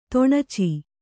♪ to'ṇaci